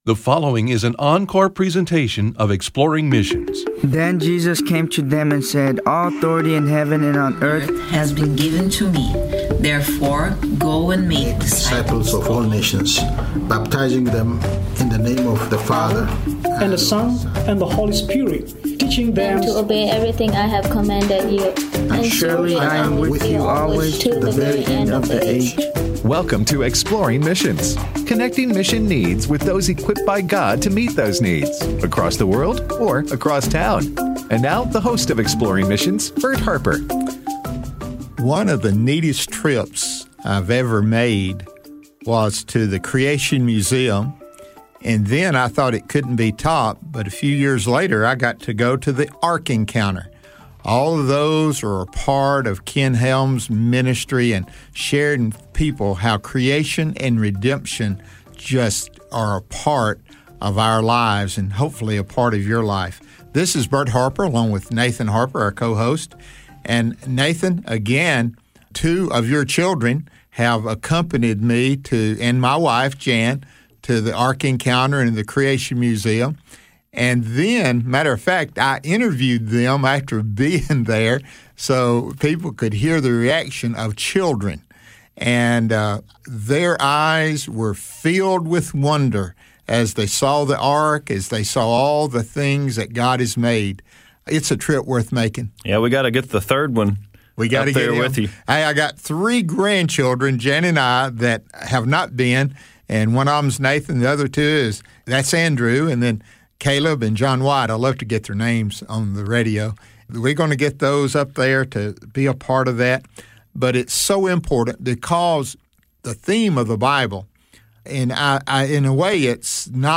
Creation and Redemption: A Conversation